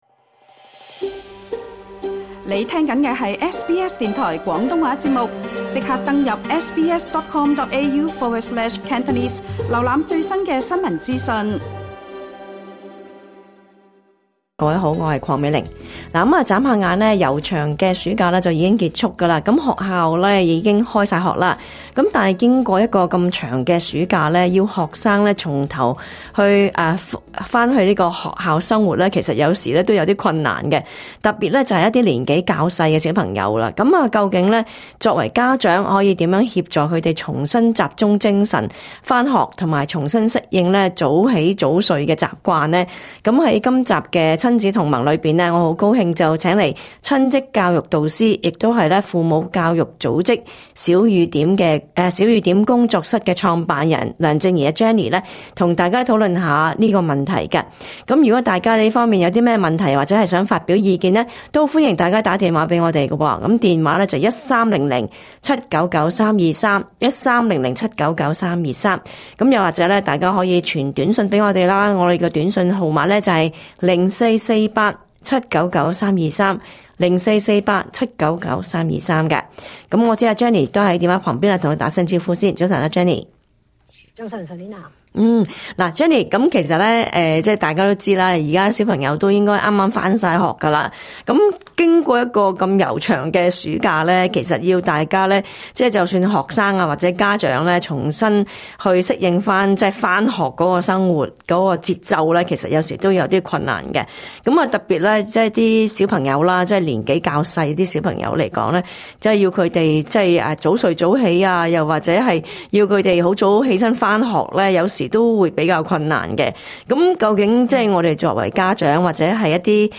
今集【親子同萌】請來親職教育導師討論，經過漫長暑假，家長可以如何協助小朋友重新集中精神上學?